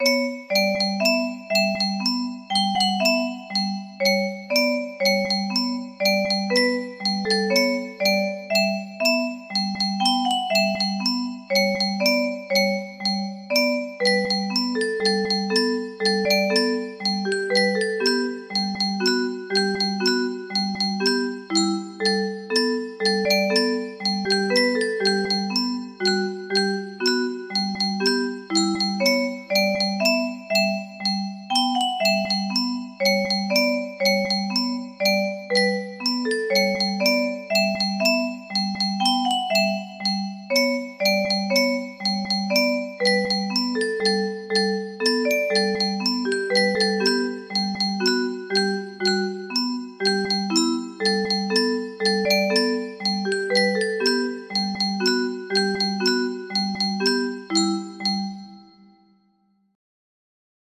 Zeynebim 3 music box melody